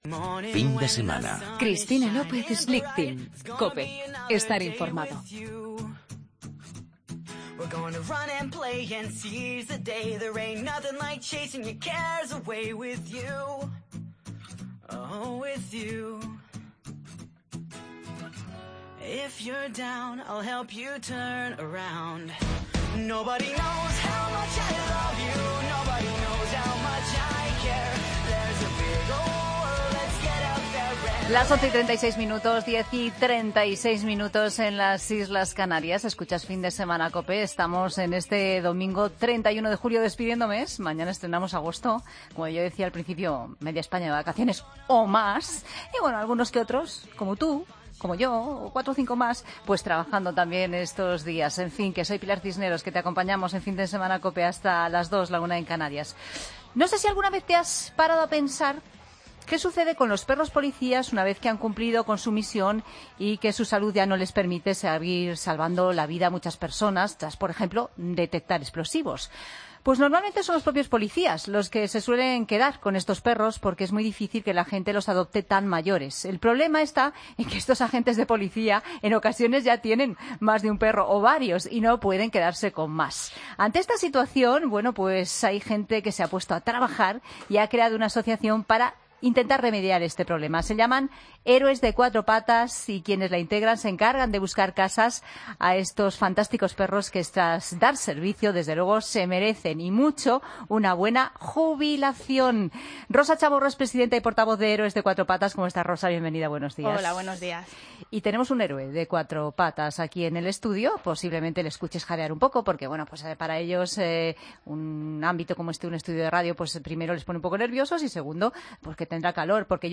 AUDIO: Entrevista de lo más curiosa a un personaje completamente distinto a todos los que visitan nuestros estudios.